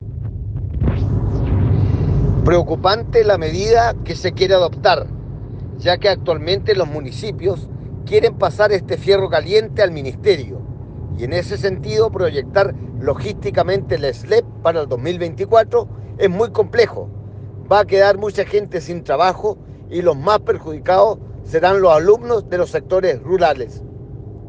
En la oportunidad, el diputado expresó su preocupación por la implementación de los SLEP, desde el punto de vista logístico y de conectividad: